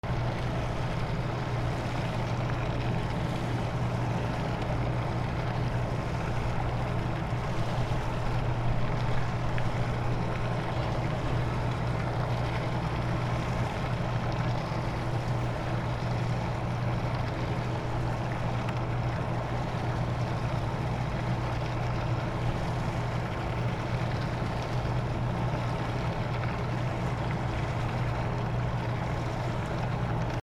/ B｜環境音(自然) / B-10 ｜波の音 / 波の音
フェリー乗り場 高松 フェリーエンジン音
ゴゥー